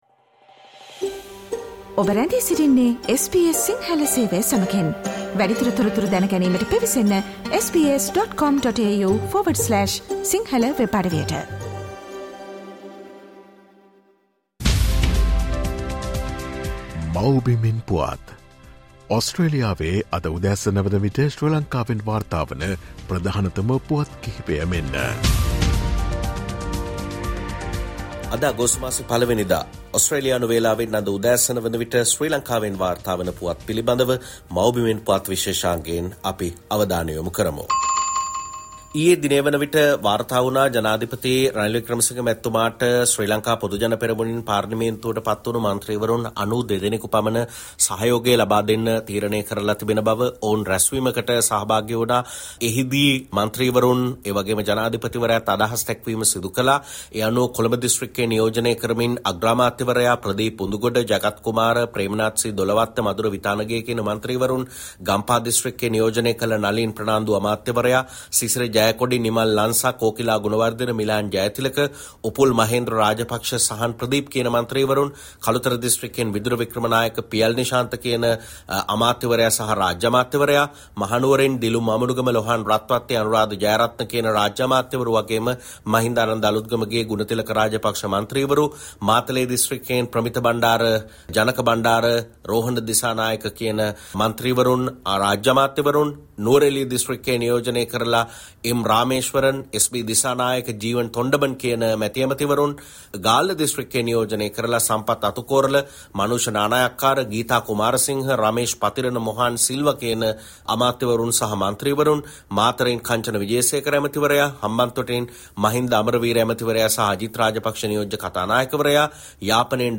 " Homeland News" featuring the latest news from Sri Lanka.